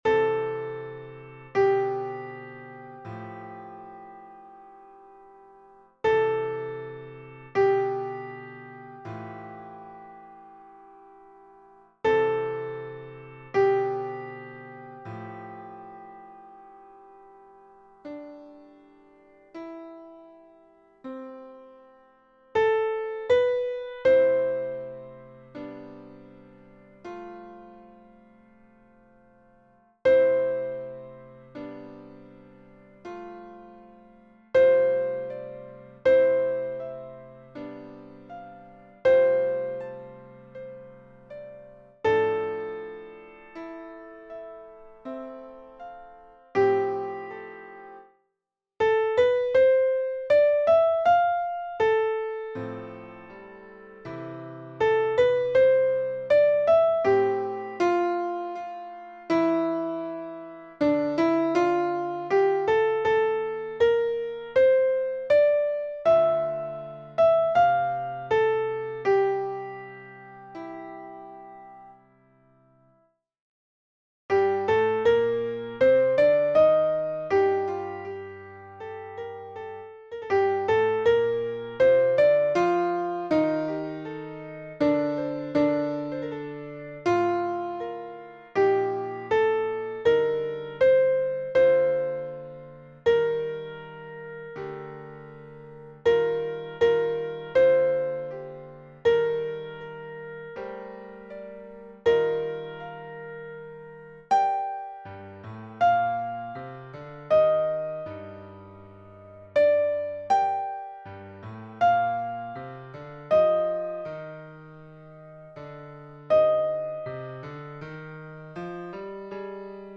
Soprano (version piano)